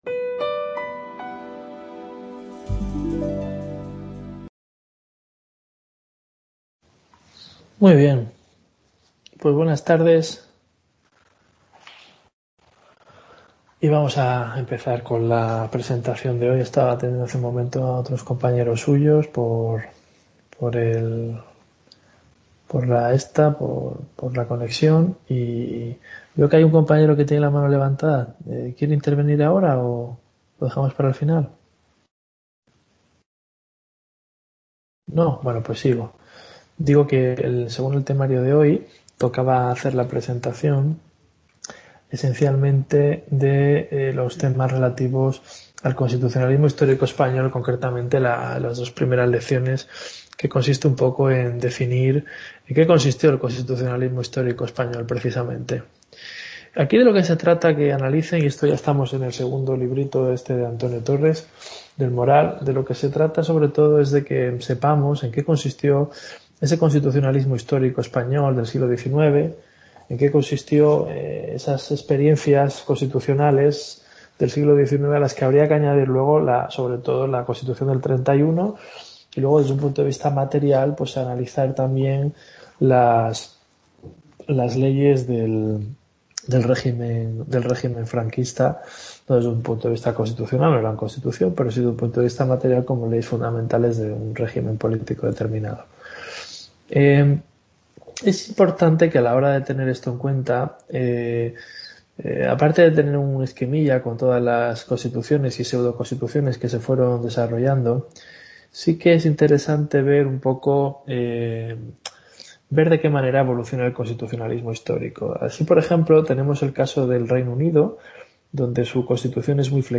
Tutoría UNED Portugalete